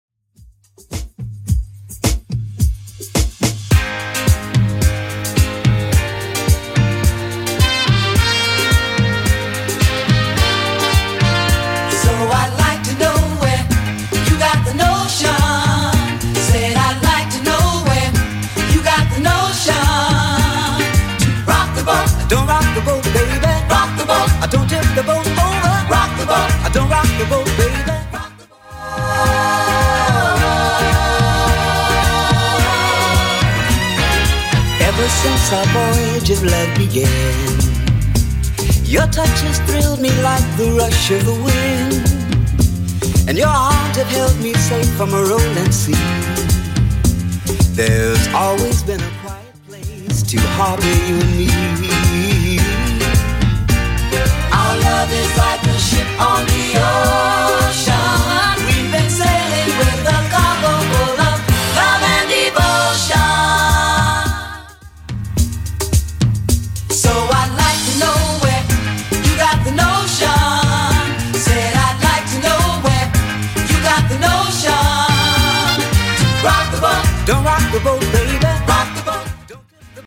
Genre: 80's
BPM: 122